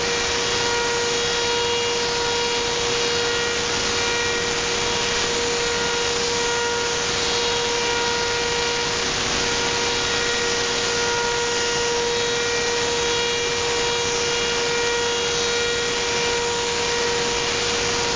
Oddity_480Hz_am.mp3